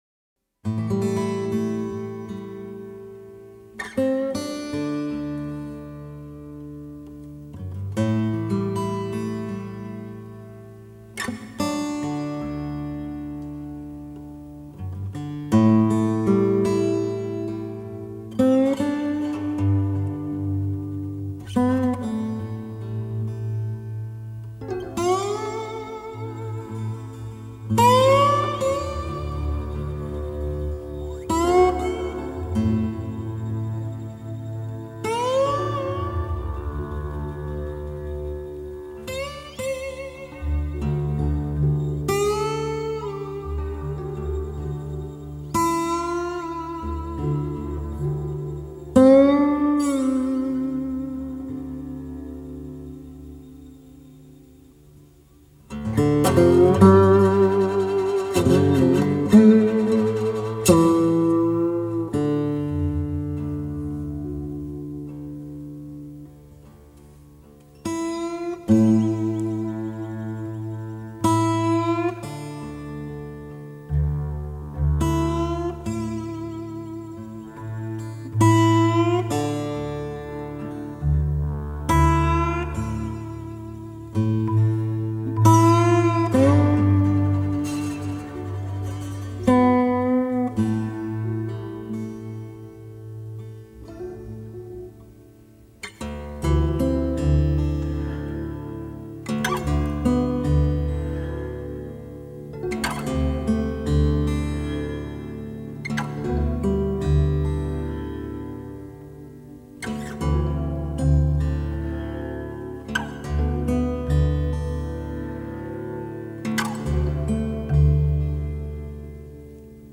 每每听到那空灵、孤独的滑棒吉他弦音，听者的思绪会飘到美国西部辽阔而荒芜的沙漠，最后消失在低垂的夜幕中。